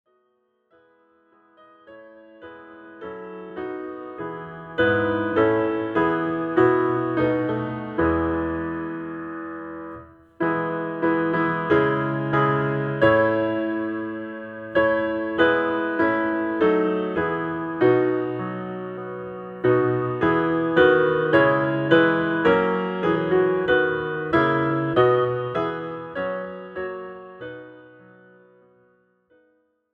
This is an instrumental backing track cover.
• Without Backing Vocals
• No Fade